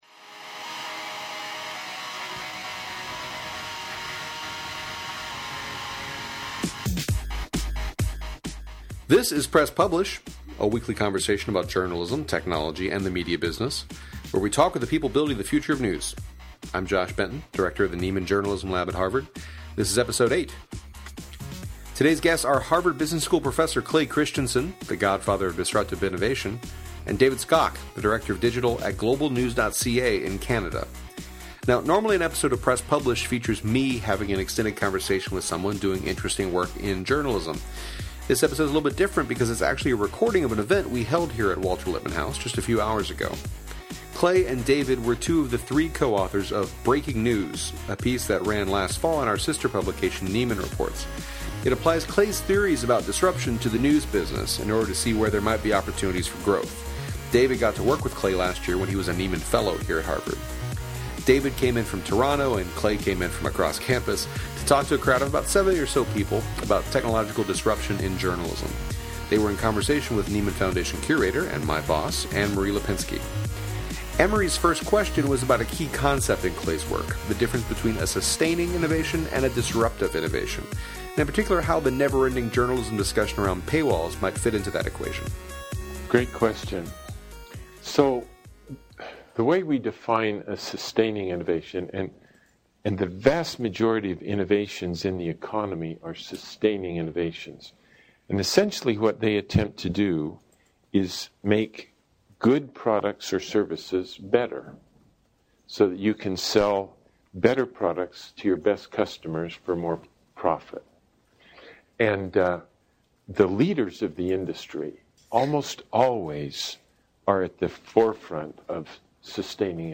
This one’s different — it’s actually a recording of an event we held here at the Nieman Foundation last night.